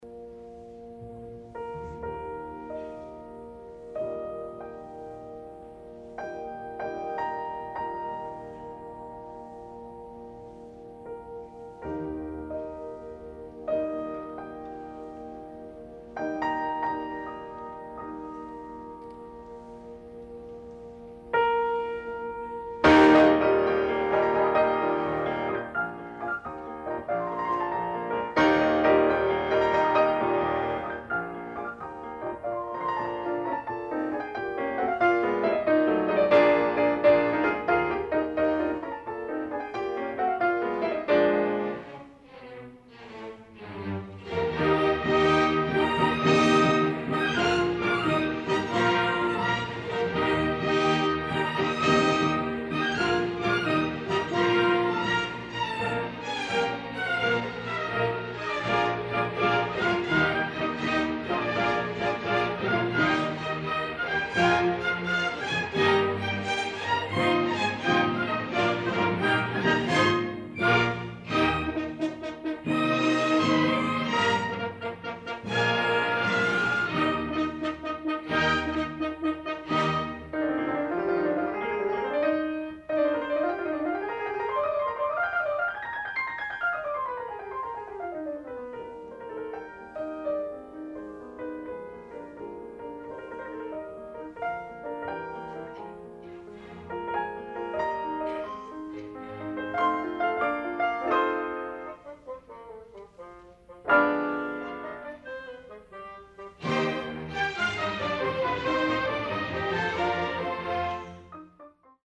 Additional Date(s)Recorded October 7, 1966 in the Will Rogers Memorial Auditorium, Fort Worth, Texas
Short audio samples from performance